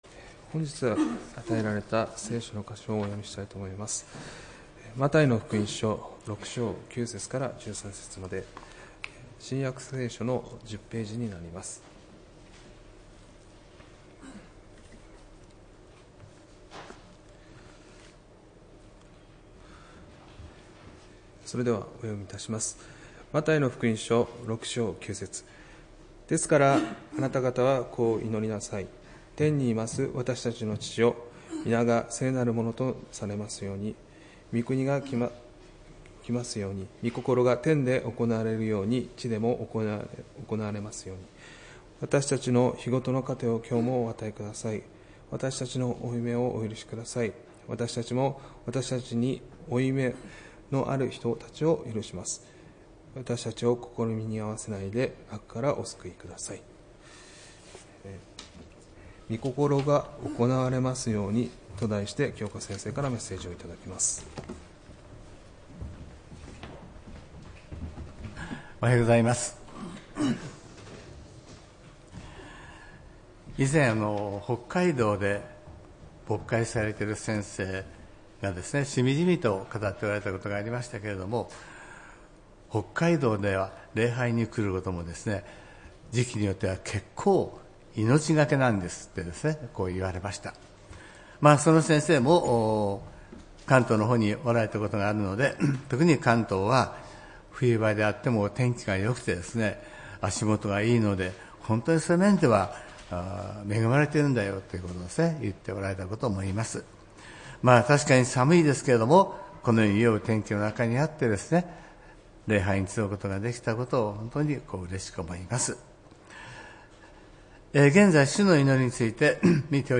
礼拝メッセージ「救いへの導き」(１月19日）